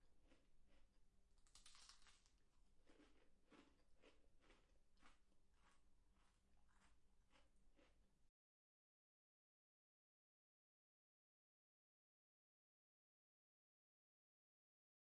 描述：吃脆脆的薯条
Tag: 薯片 用力咀嚼 咀嚼 OWI 小吃 紧缩